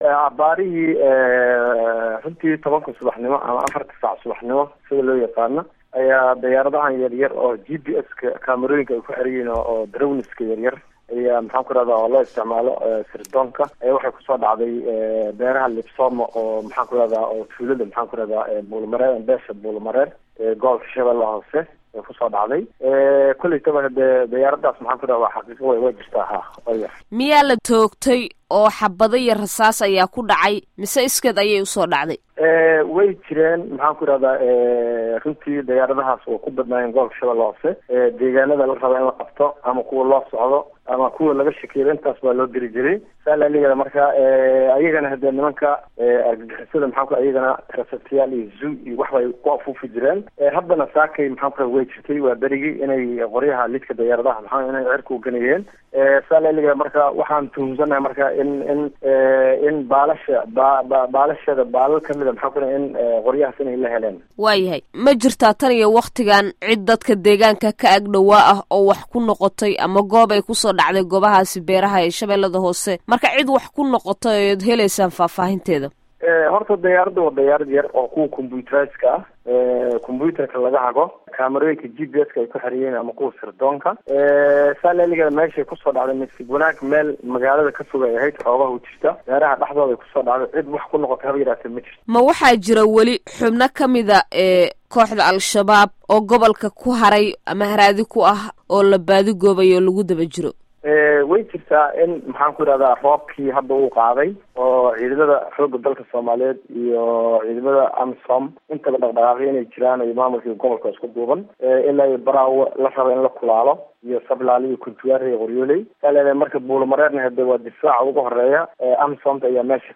Waraysiga Guddoomiyaha Gobolka Shabeelada Hoose